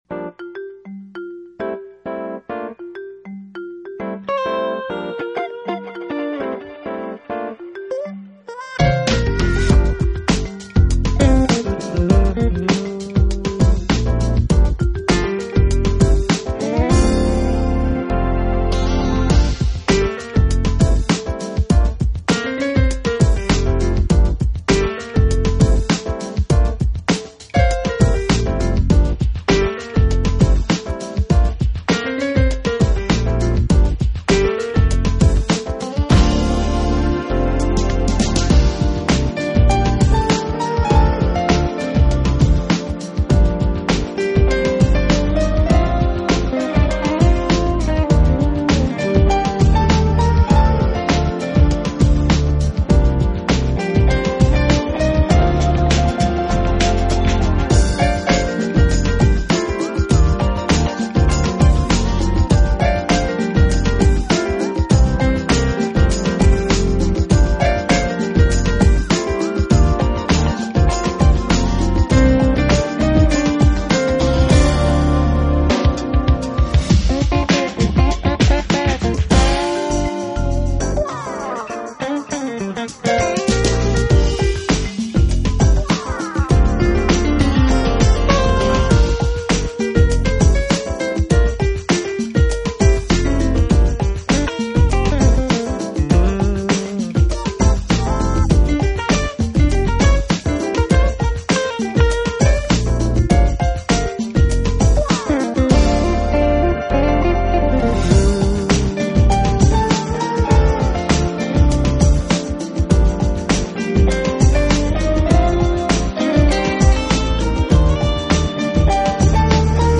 专辑类型：Smooth Jazz
们的Smooth Jazz作品中融入了大量现代电子舞曲元素，而在器乐演奏方面则又Higher